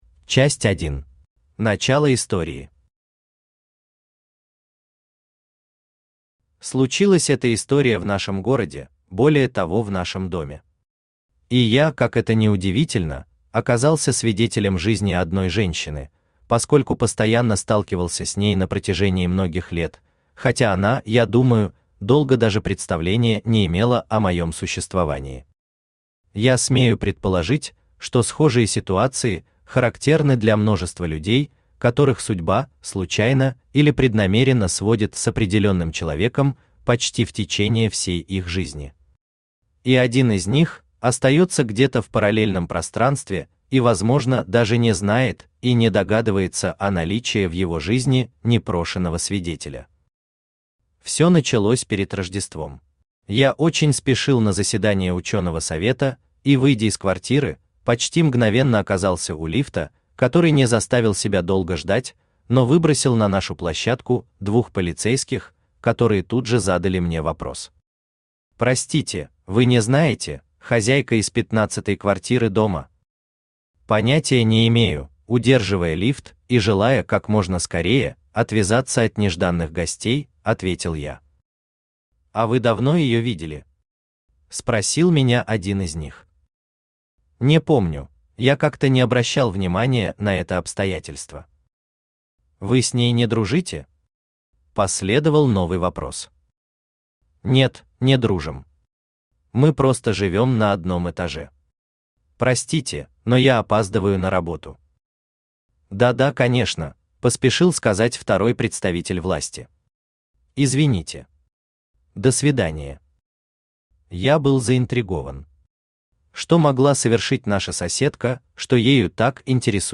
Аудиокнига Тайное частное расследование | Библиотека аудиокниг
Aудиокнига Тайное частное расследование Автор Жанна Светлова Читает аудиокнигу Авточтец ЛитРес.